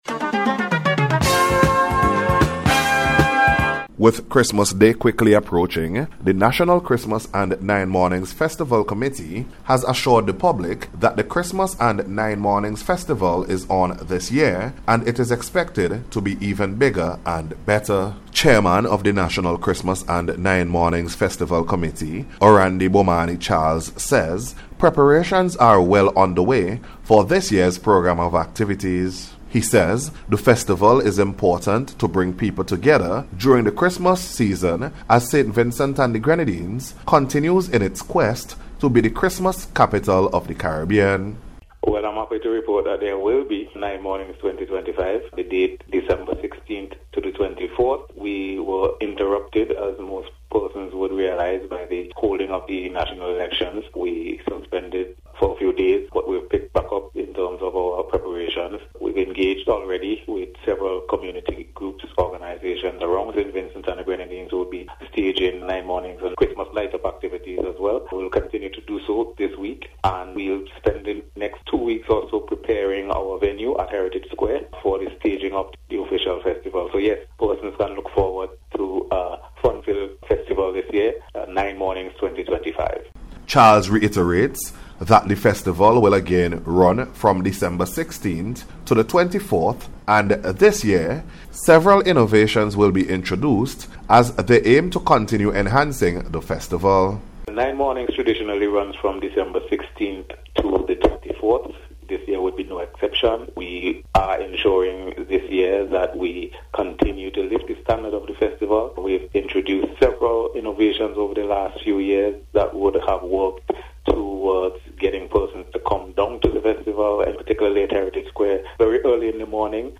NATIONAL-CHRISTMAS-AND-NINE-MORNINGS-REPORT.mp3